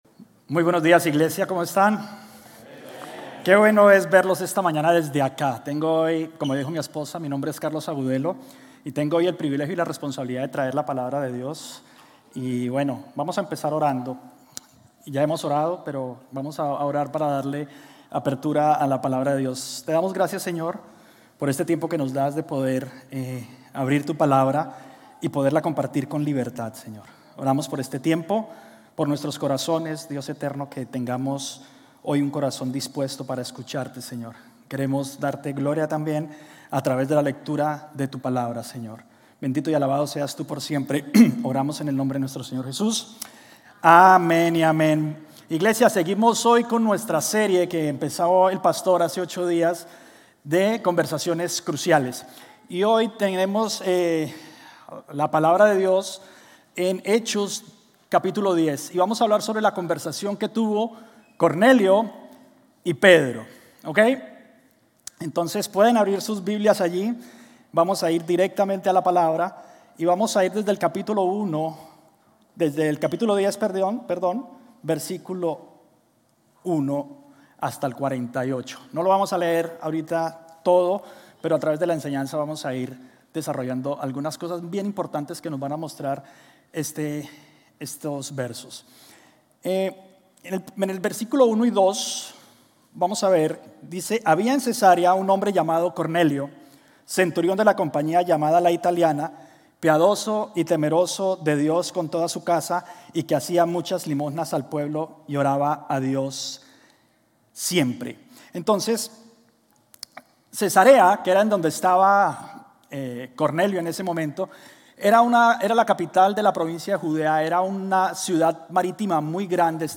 GCC-GE-October-15-Sermon.mp3